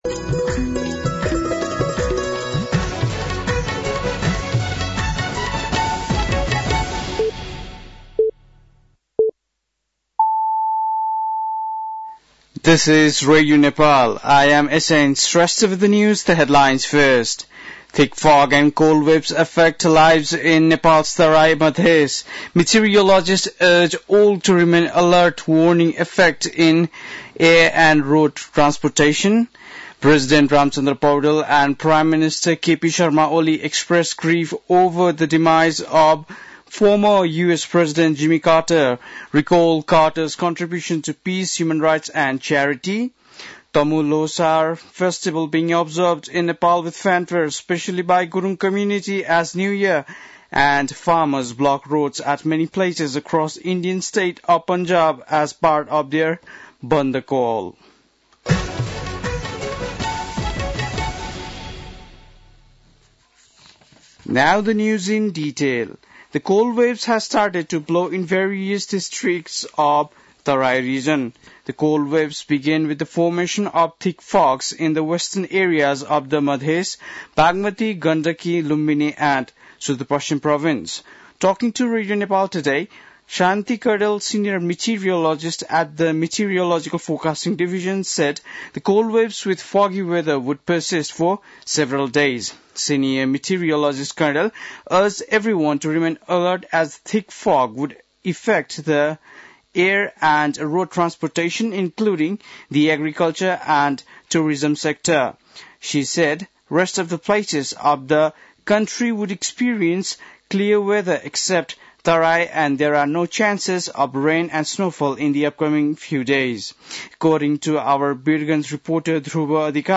बेलुकी ८ बजेको अङ्ग्रेजी समाचार : १६ पुष , २०८१
8-PM-English-News-9-15.mp3